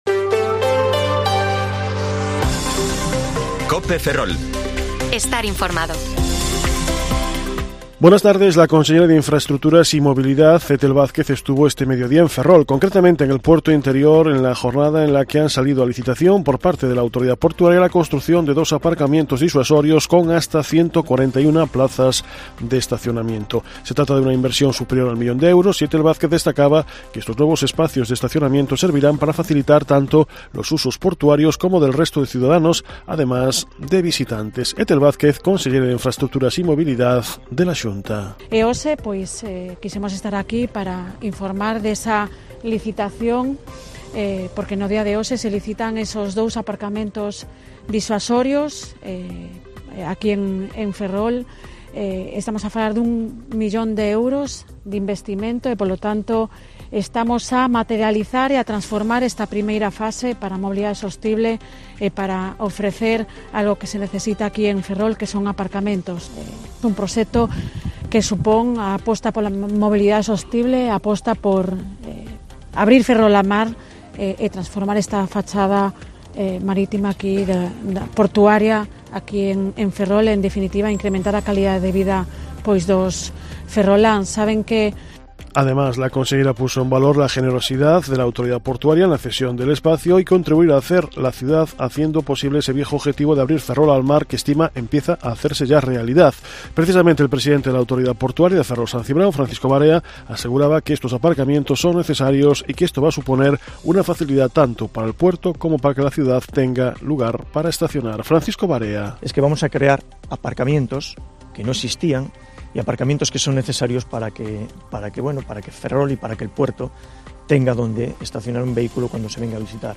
Informativo Mediodía COPE Ferrol 3/2/2023 (De 14,20 a 14,30 horas)